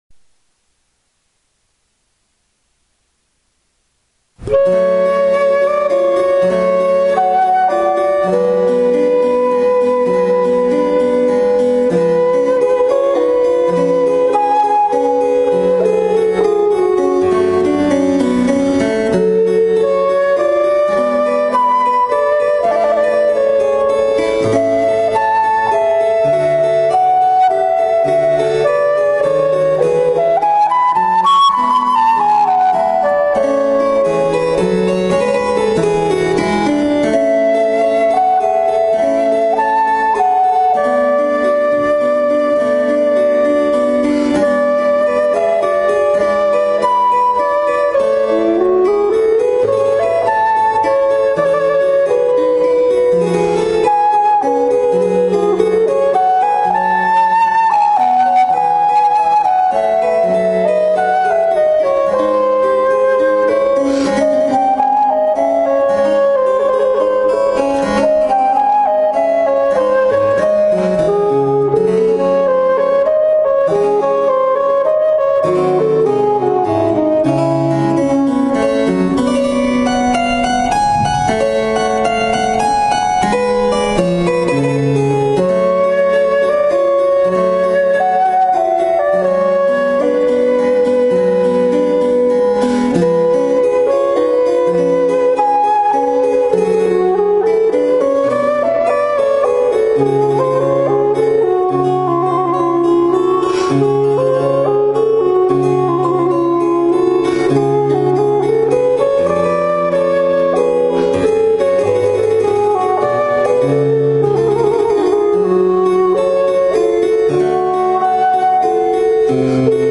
楽器特集：ﾌﾙｰﾄ
ﾊﾞﾛｯｸ･ﾌﾙｰﾄ
ﾁｪﾝﾊﾞﾛ